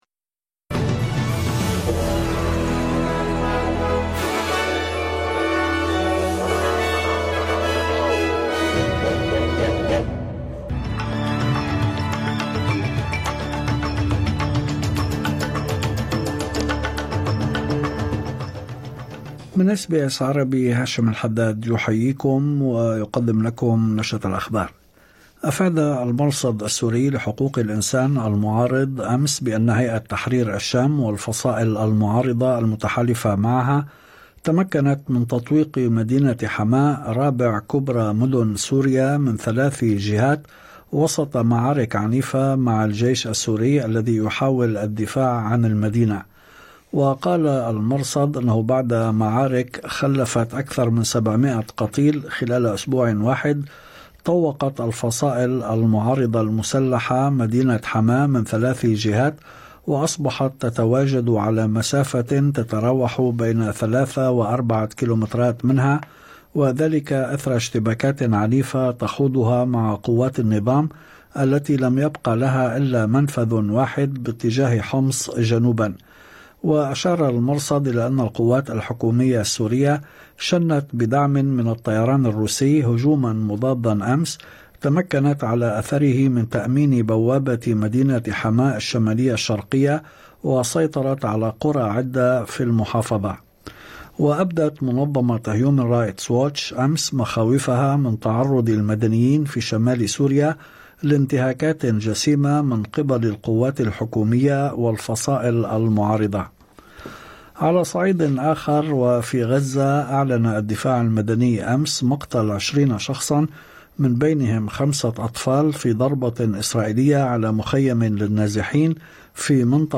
نشرة أخبار الظهيرة 5/12/2024